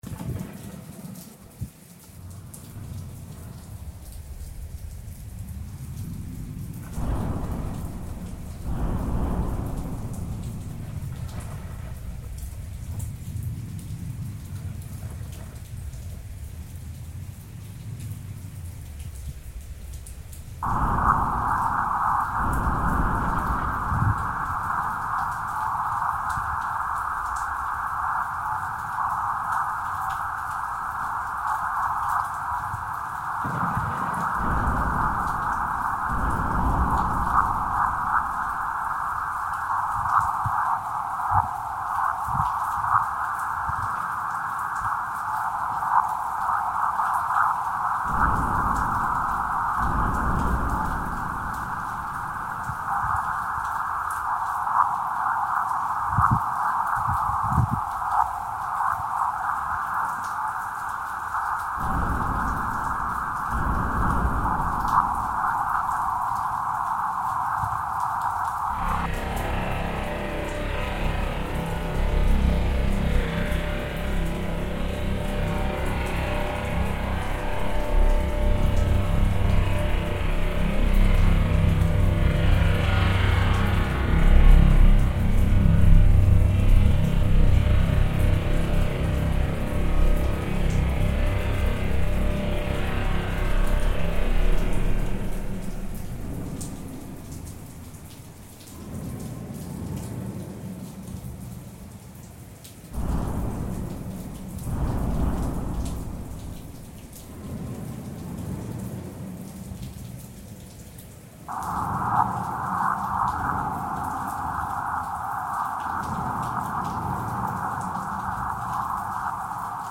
the sound of thunder and rain on Stromboli, Sicily.